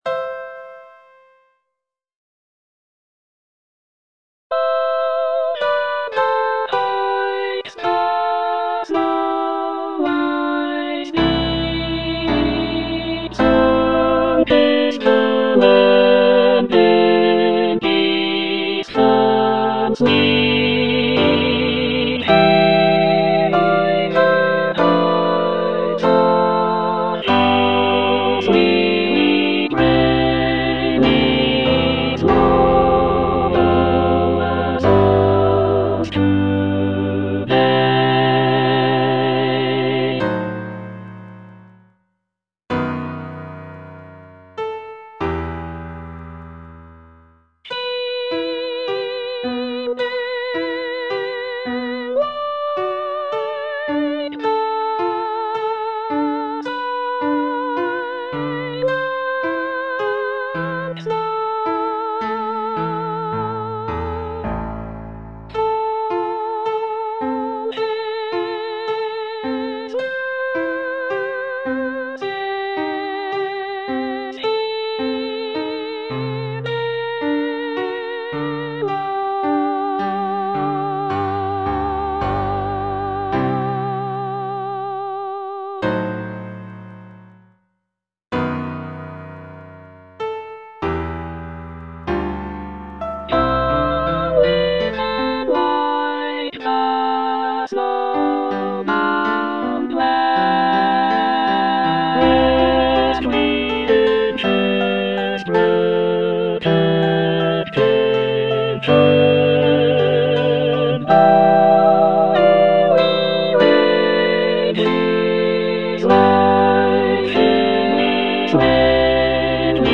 E. ELGAR - FROM THE BAVARIAN HIGHLANDS Aspiration (All voices) Ads stop: auto-stop
The music captures the essence of the picturesque landscapes and folk traditions of the area, with lively melodies and lush harmonies.